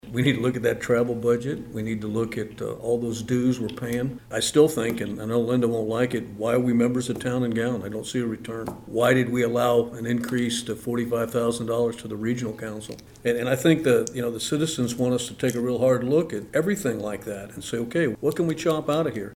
Commissioner Wynn Butler said, for him, this number is just a starting point.